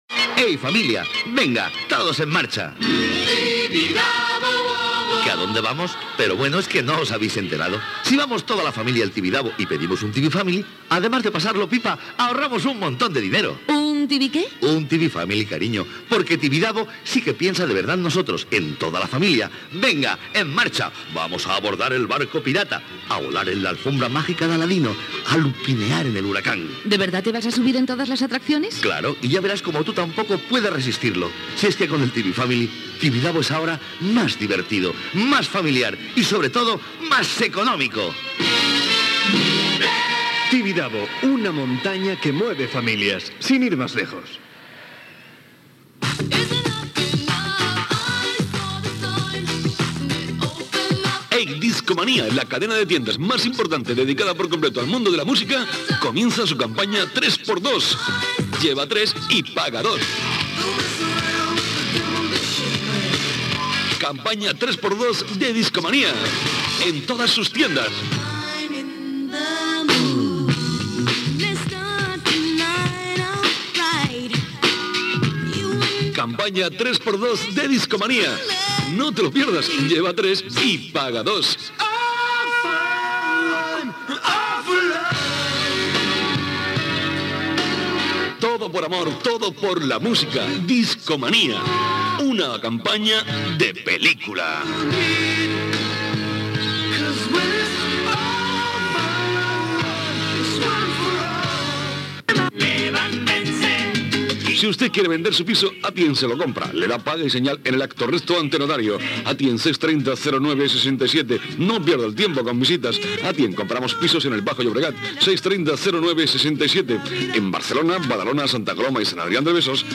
Publicitat, promoció del programa "De todo un poco"